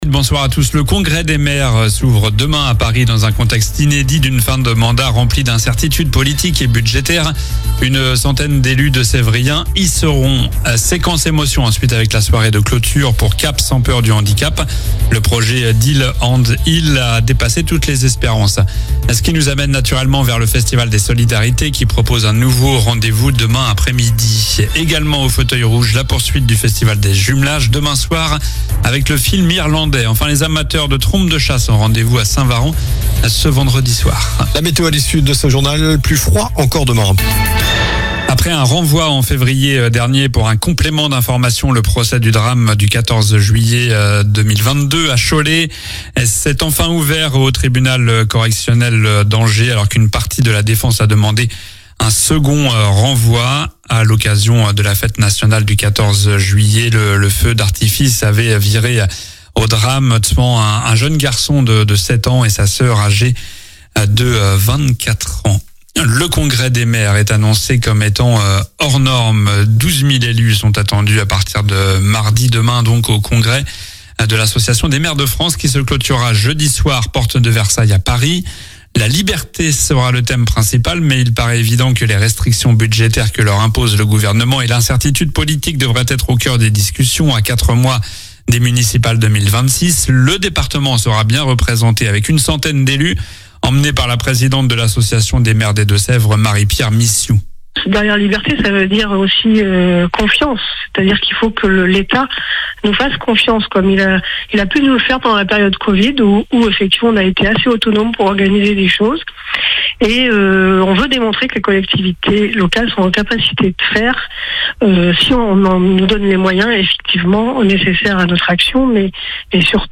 JOURNAL DU LUNDI 17 NOVEMBRE ( soir)